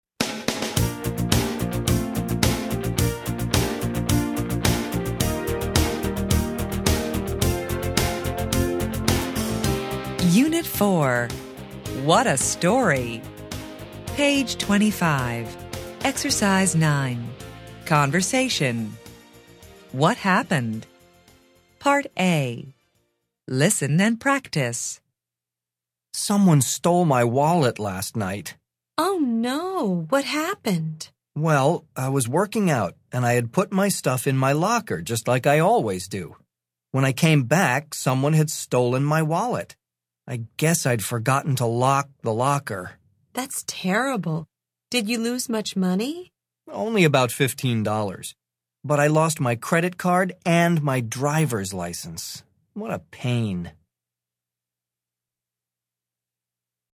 interchange3-level3-unit4-ex9-conversation-track7-students-book-student-arcade-self-study-audio.mp3